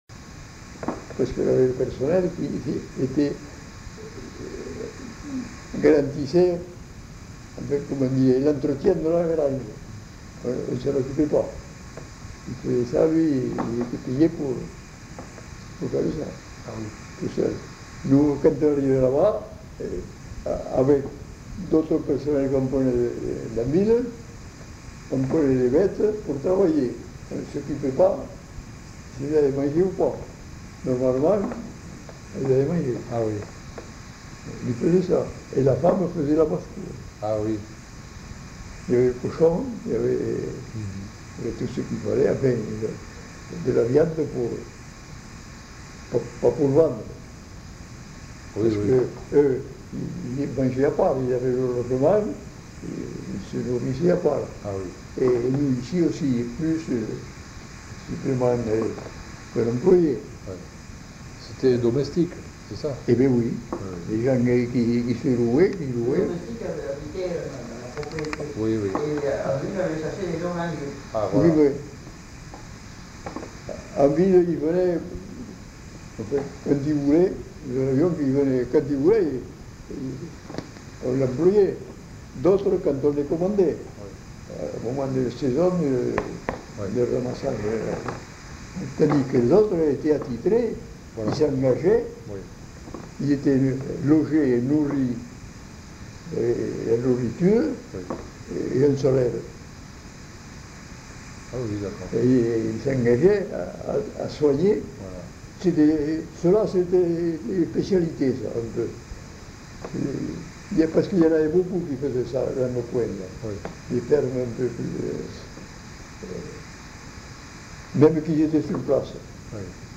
Aire culturelle : Haut-Agenais
Genre : témoignage thématique